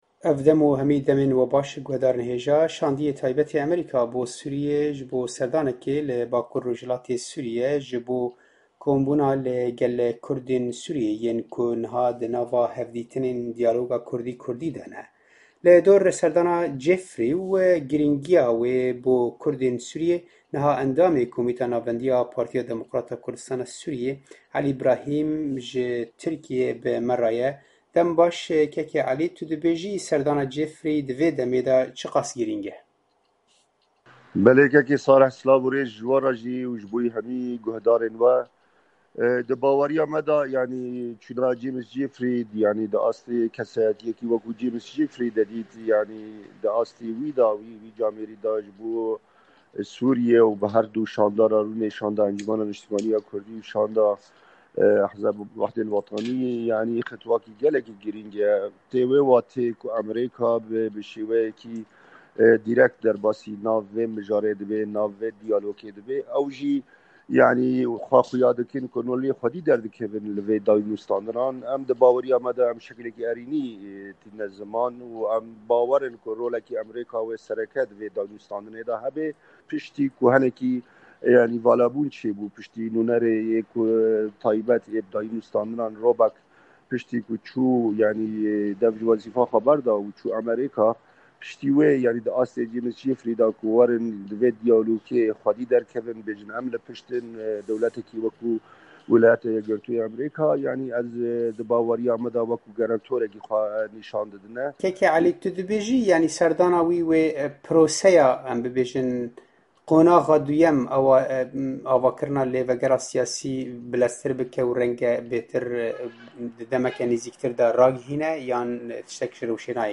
Hevpeyvîna